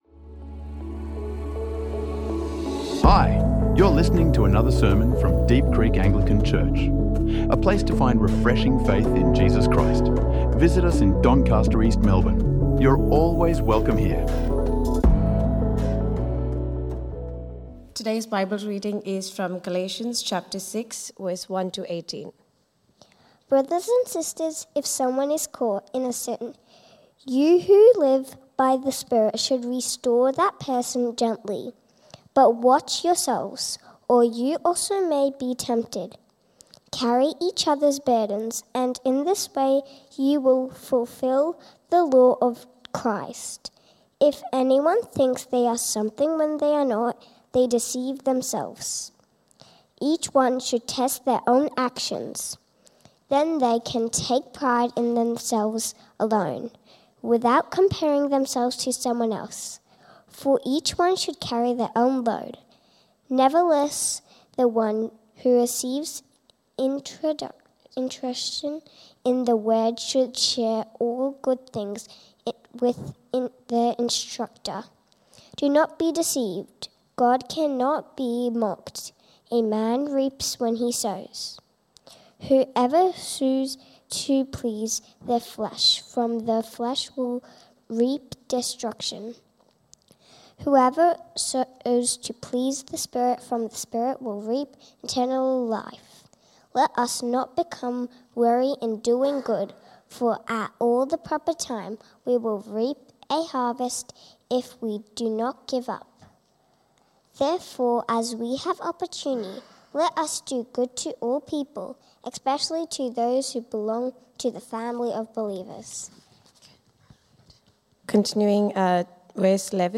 The Community of the Free | Sermons | Deep Creek Anglican Church